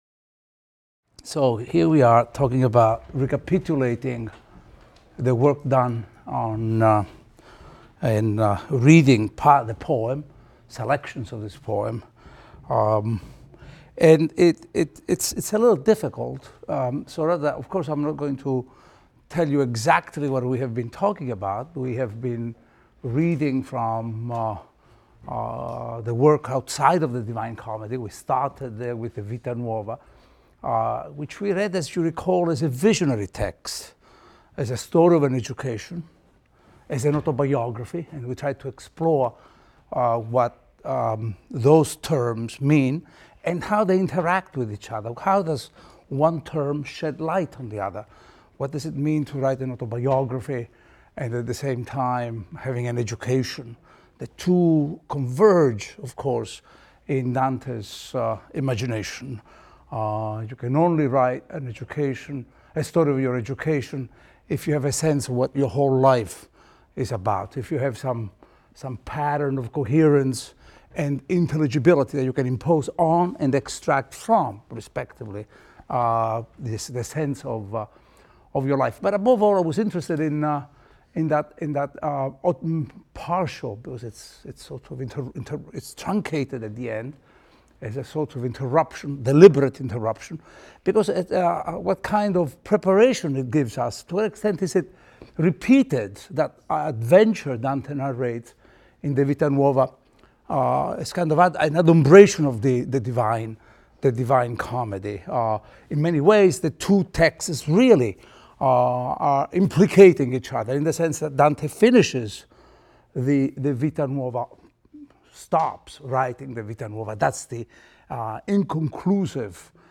ITAL 310 - Lecture 24 - General Review | Open Yale Courses